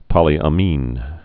(pŏlē-ə-mēn, -ămēn)